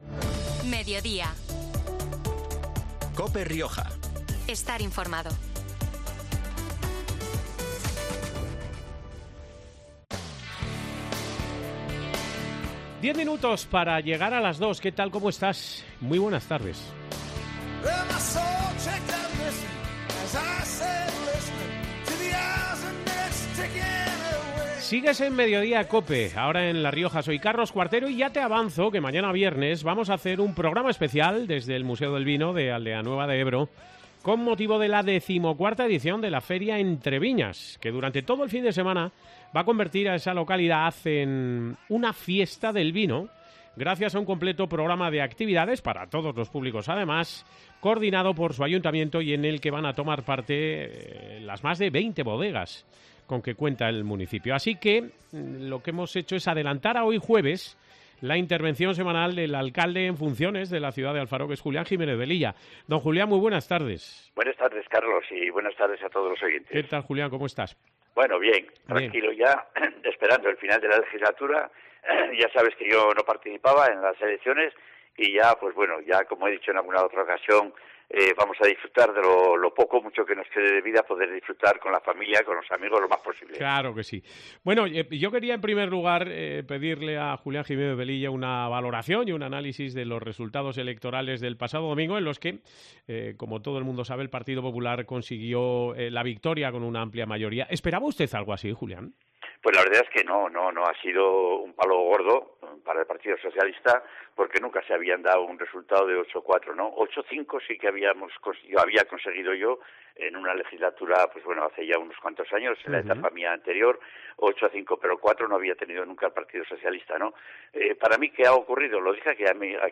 Ese es el primer análisis que ha ofrecido en COPE Rioja el todavía alcalde socialista de Alfaro, Julián Jiménez Velilla.